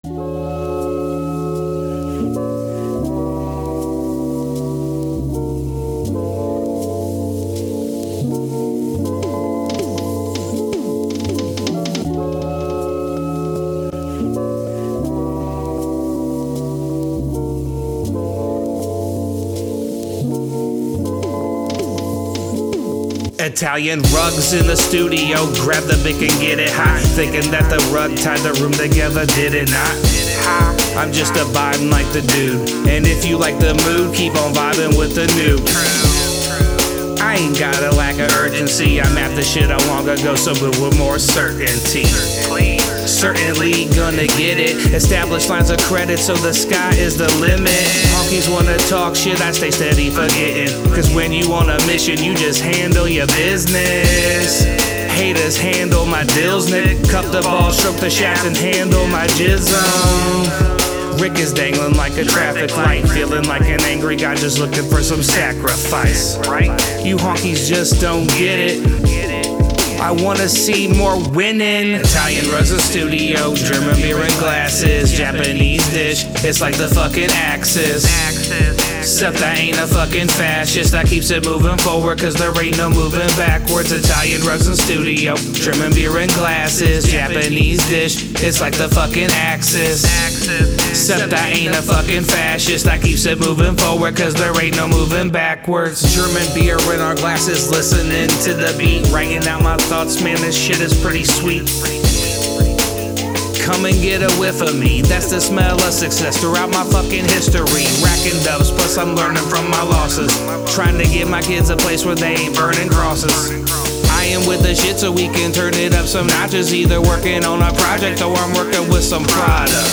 Hiphop
Hip Hop style of music